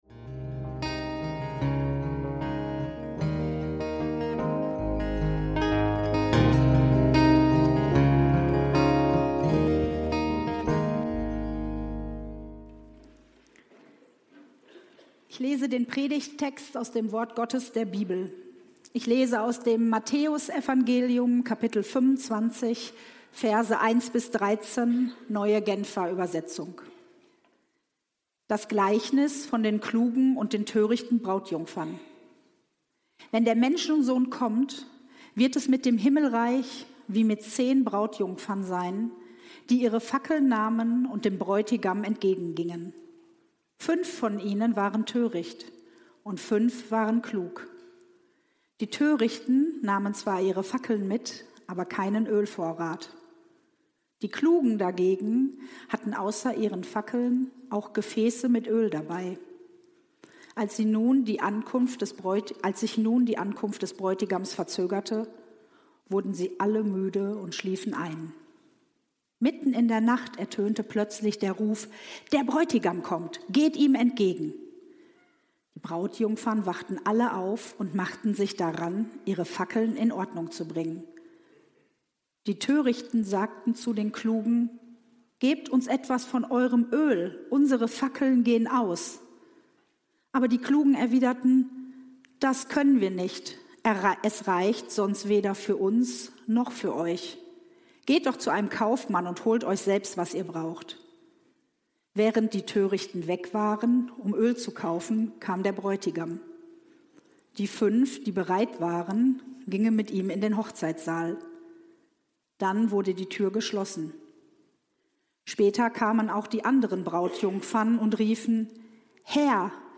Lasst euer Licht leuchten – Vorbereitet Jesus entgegenleben – Predigt vom 23.11.2025